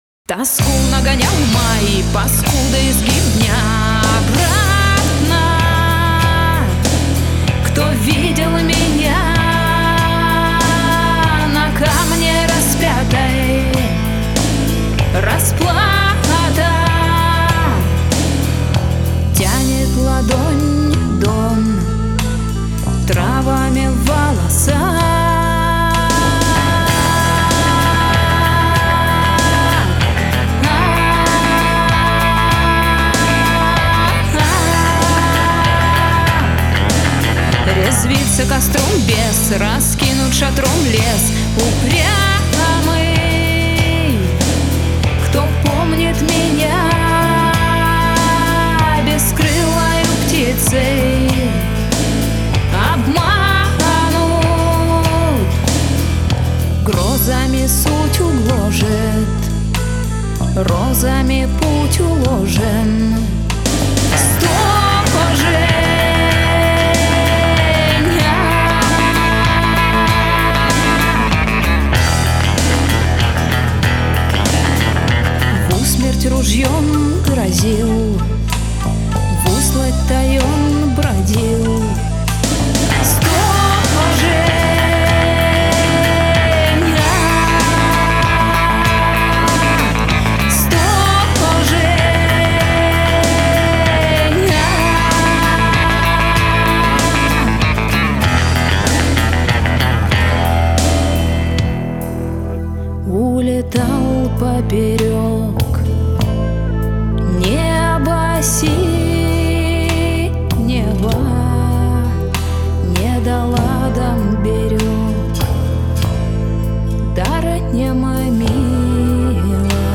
Стиль: фолк-рок